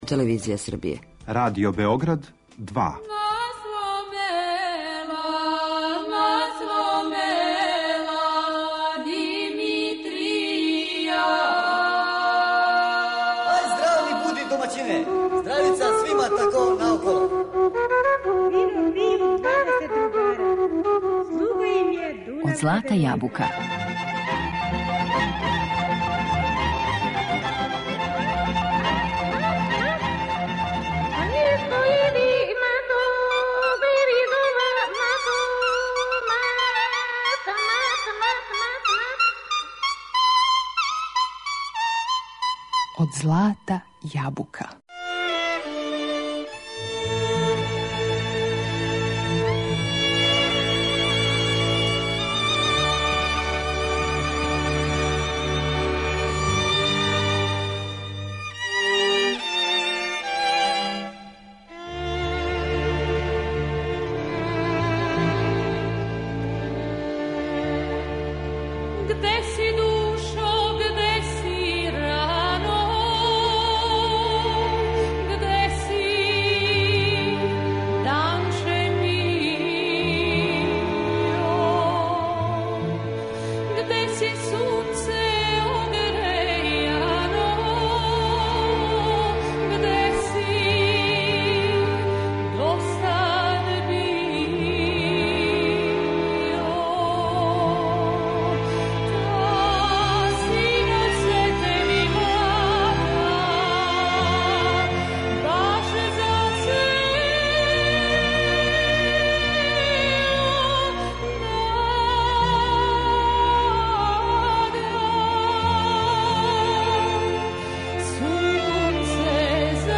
Музички портрет Мериме Његомир - други део